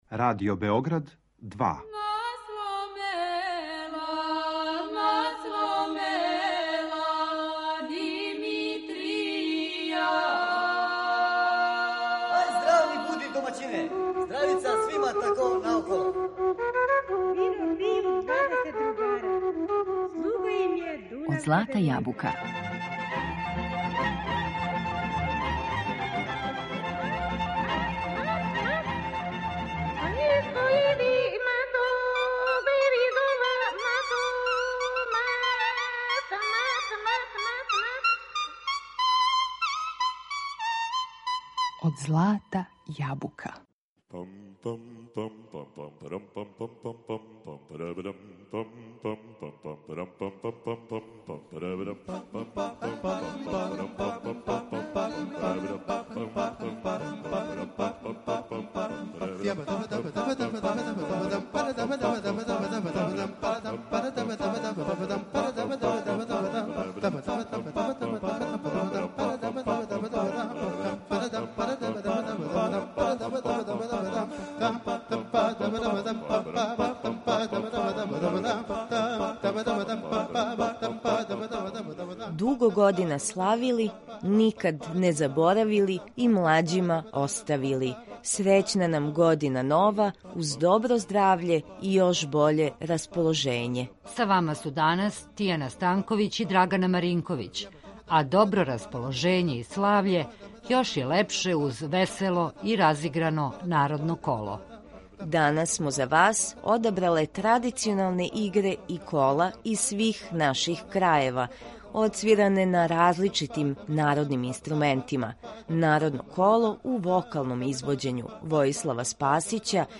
Добро расположење и славље још je лепше уз весело и разиграно народно коло. Одабрале смо за вас традиционалне игре и кола из свих наших крајева, одсвиране на различитим традиционалним инструментима.
хармоници
виолину
трубе